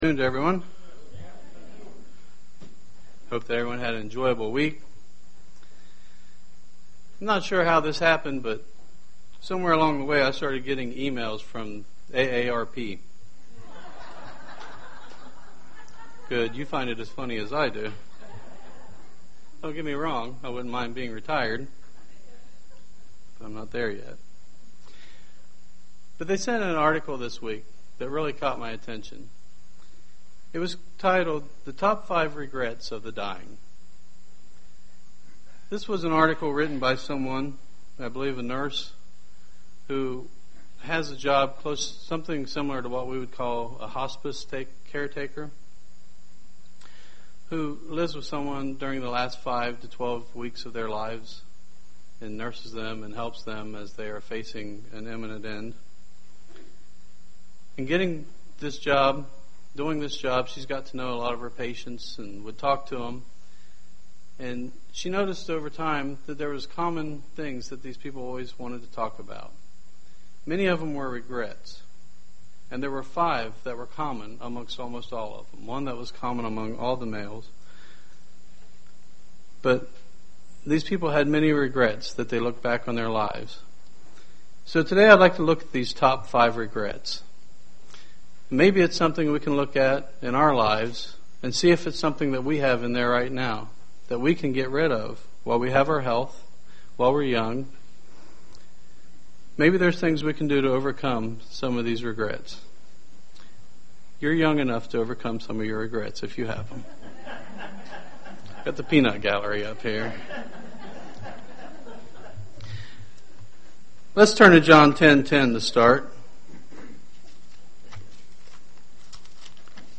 Split sermon that takes a look at the top five regrets of terminal patients.
Given in Dayton, OH